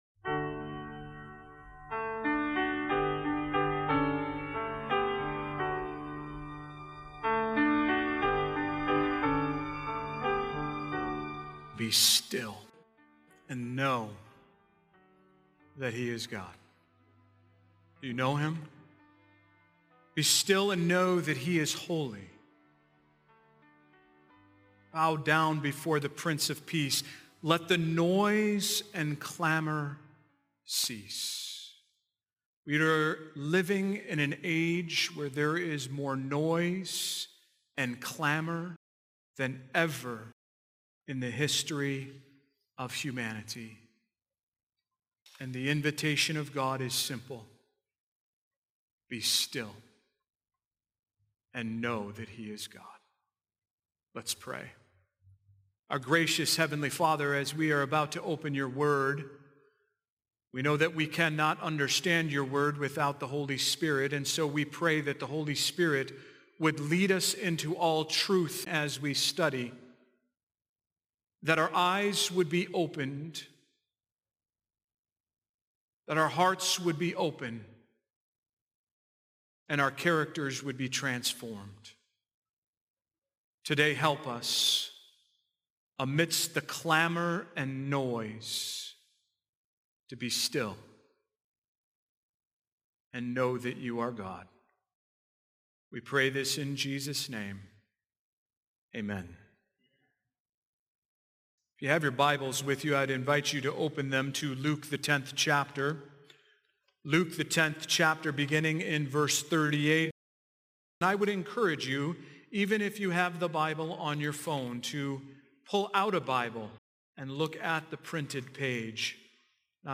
Are you busy doing for God but missing time with Him? This powerful sermon unpacks the story of Mary and Martha, revealing how true transformation begins with spiritual intimacy, humble listening, and prioritizing presence over performance.